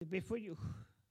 Localisation Soullans
Catégorie Locution